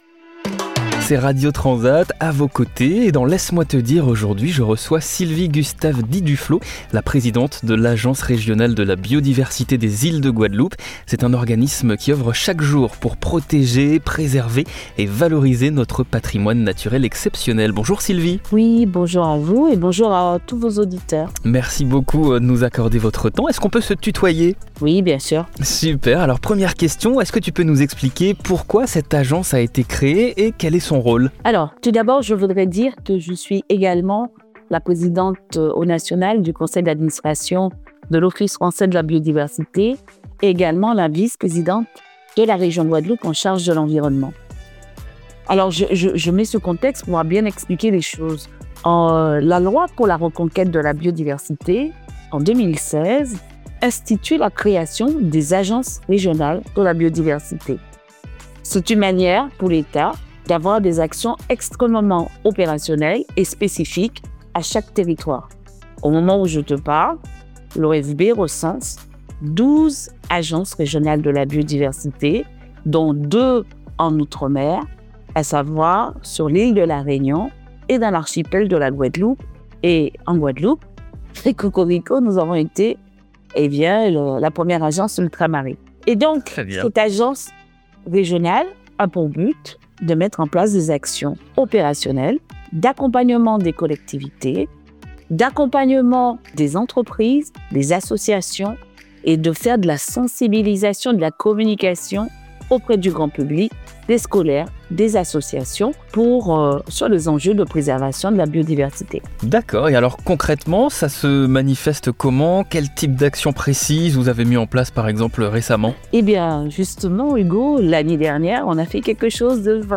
Dans cette interview, Sylvie GUSTAVE DIT DUFLO nous présente l’Agence régionale de la biodiversité des Îles de Guadeloupe, organisme qui œuvre pour protéger, préserver et valoriser notre patrimoine naturel.